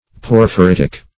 Porphyritic \Por`phy*rit"ic\, a. [Cf. F. porphyritique.]